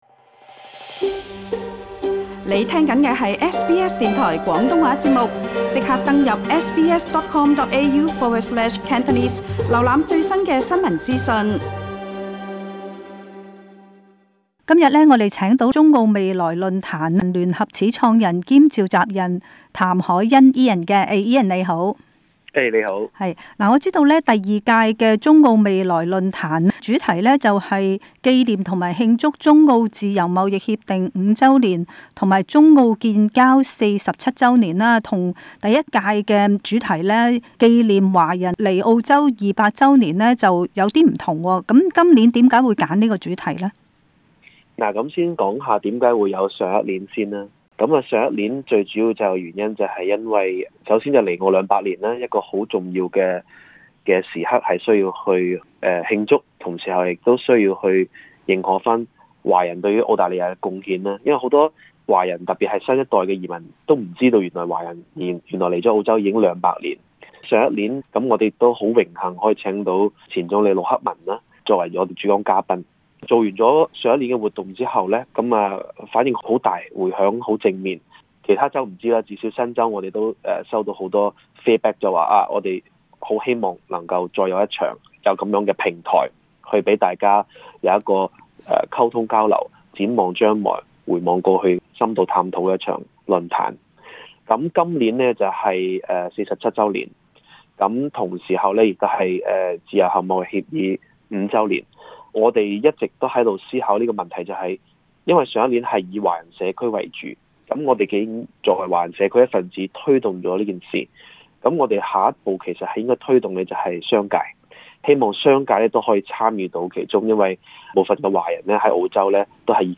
【社區專訪】中澳未來論壇